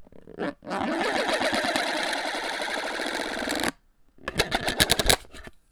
• serrated plastic disc spinning on plastic.wav
A small serrated plastic disc rolled over a frisbee, recorded with a Tascam DR40
serrated_plastic_disc_spinning_on_plastic_wNC.wav